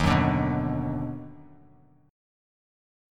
EbmM7#5 chord